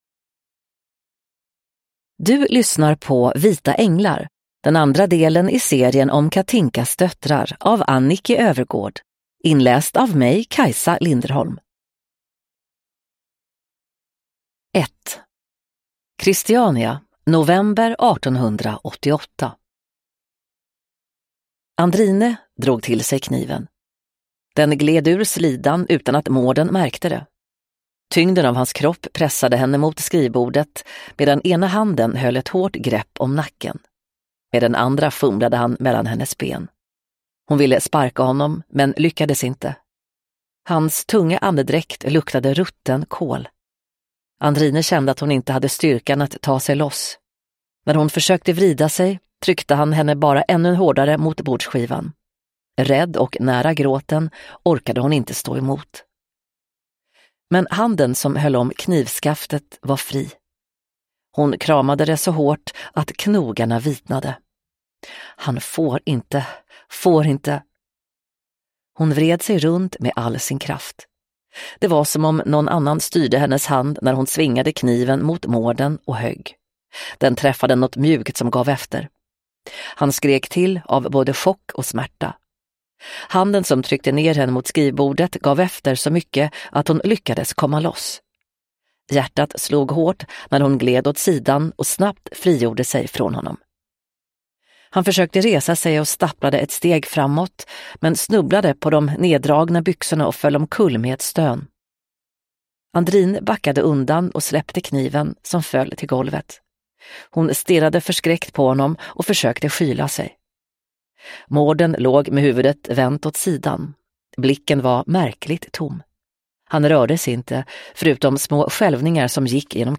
Vita änglar – Ljudbok